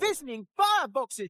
Unknown 1w ago Voice actor: Kerry Shale
Speaking Voice of Thomas The Tank Engine